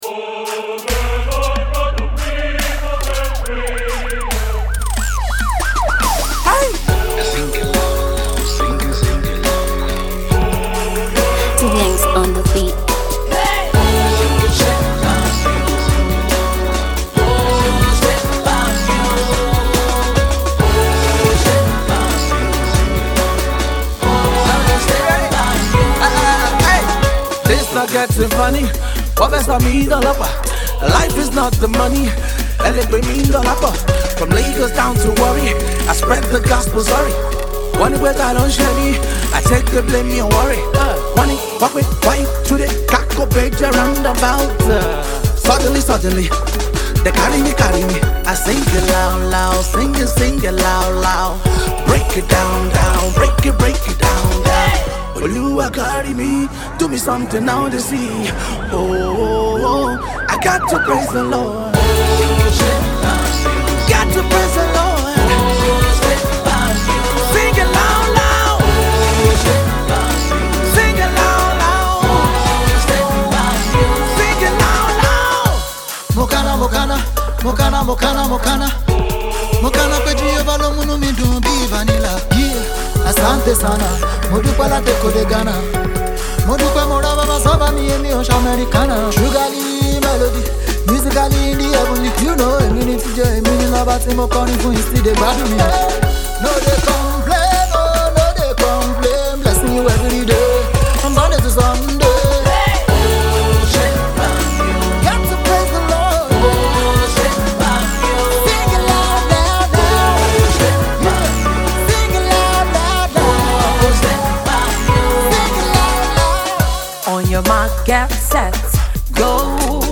is an expression by 3 rappers